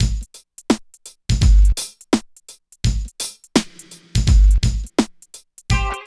137 DRM LP-R.wav